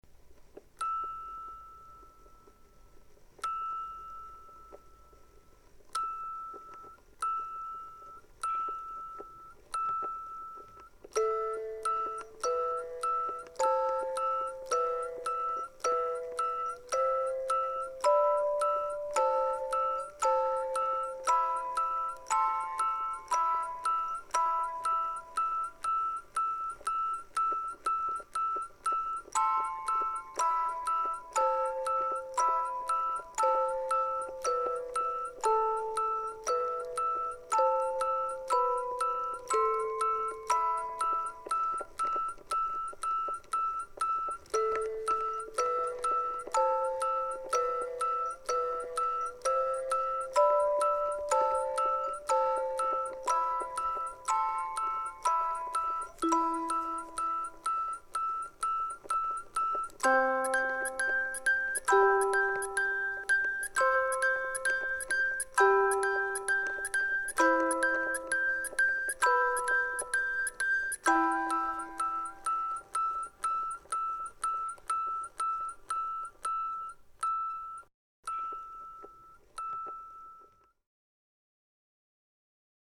ビデオとパフォーマンスのサウンドトラックとしてつくったものです。
オルゴール